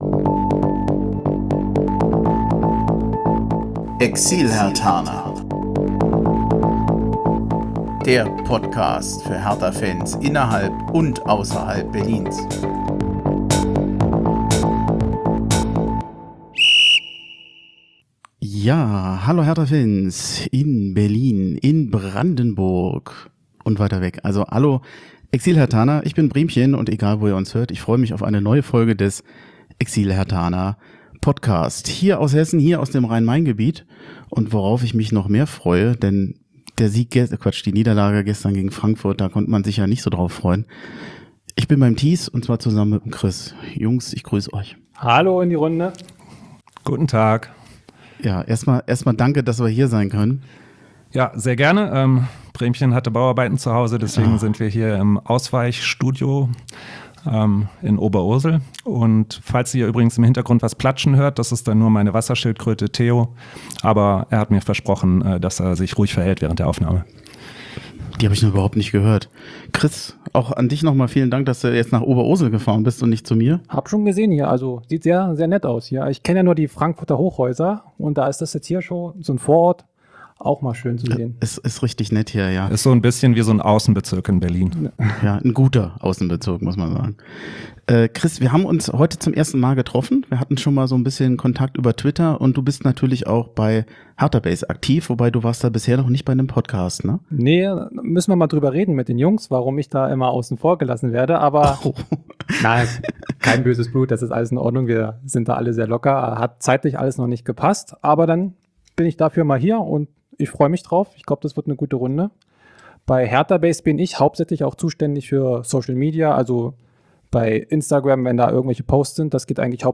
Das Spiel gegen Eintracht Frankfurt fühlte sich an, wie ein Schlag in die Magengrube. Einer desaströsen ersten Halbzeit folgte eine um Besserung bemühte zweite Halbzeit, unter dem Strich blieb es aber bei einer enttäuschenden und schmerzvollen Heimniederlage. Unsere Selbsthilfegruppe in Sachen Hertha tagte endlich mal wieder vor Ort im Rhein-Main-Gebiet.